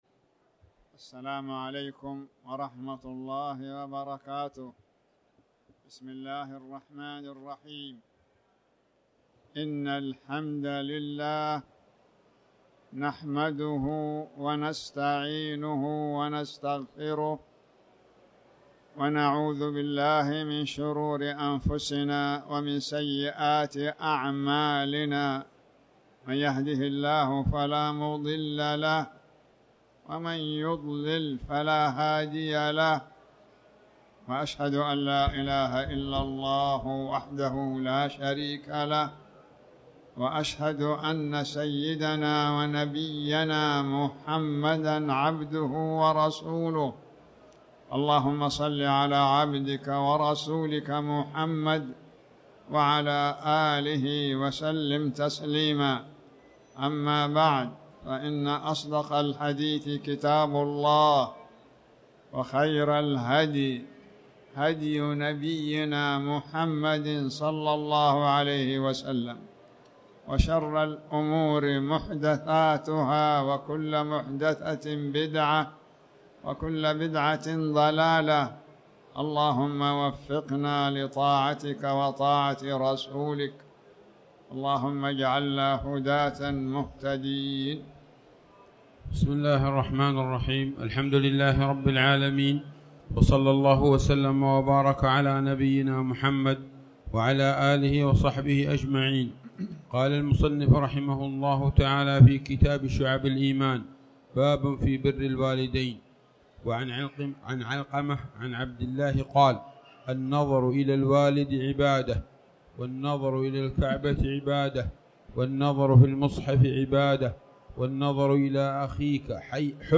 تاريخ النشر ٩ ذو القعدة ١٤٣٩ هـ المكان: المسجد الحرام الشيخ